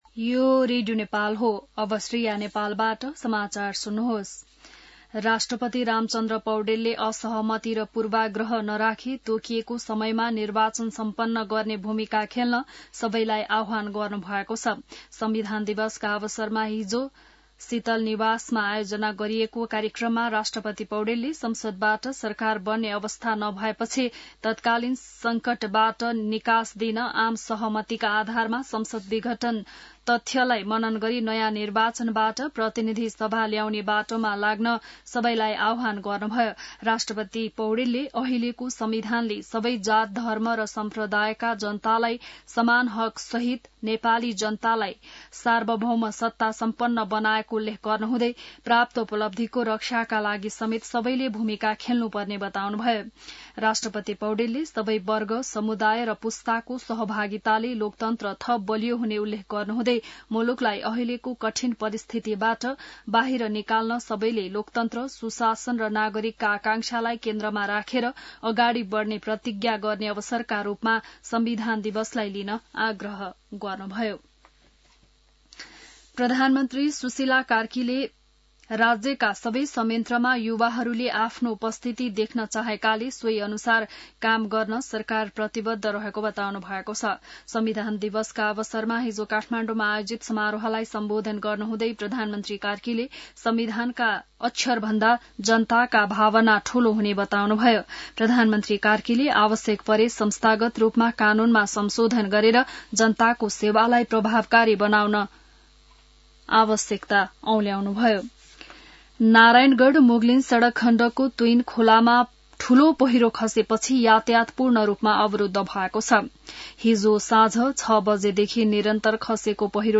बिहान १० बजेको नेपाली समाचार : ४ असोज , २०८२